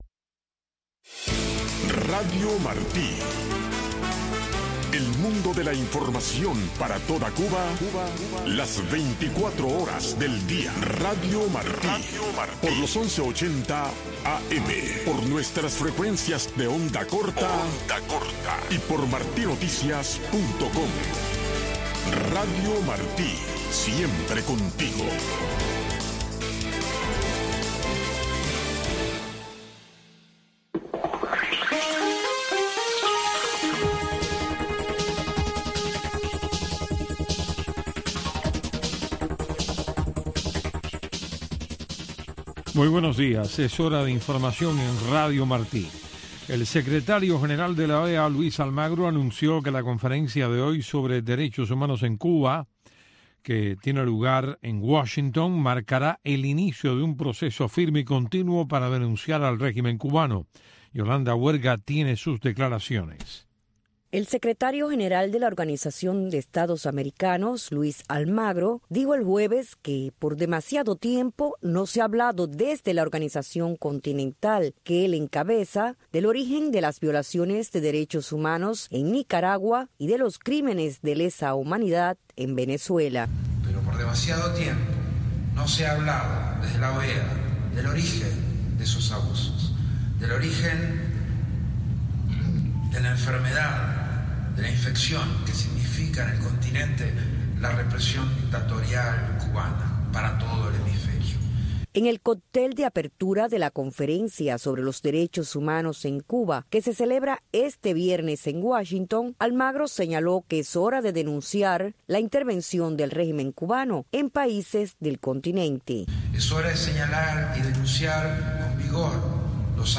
LIVE COVERAGE CONFERENCE ON THE HUMAN RIGHTS SITUATION IN CUBA